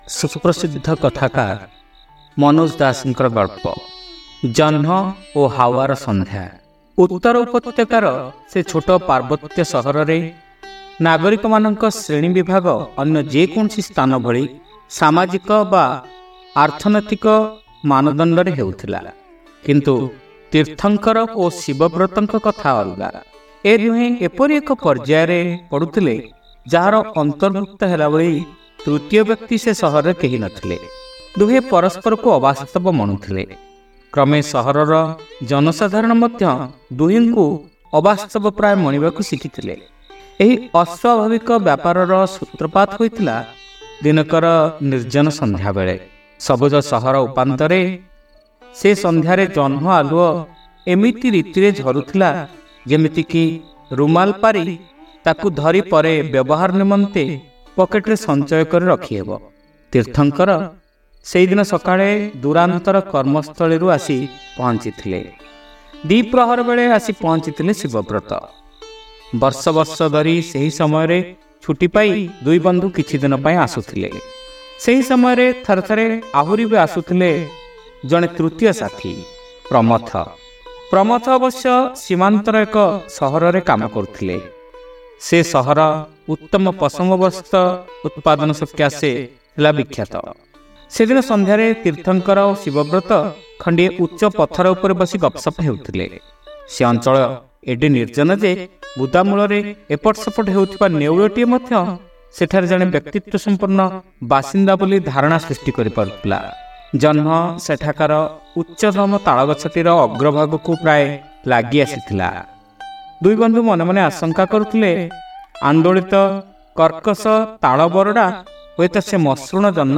ଶ୍ରାବ୍ୟ ଗଳ୍ପ : ଜହ୍ନ ଓ ହାୱାର ସନ୍ଧ୍ୟା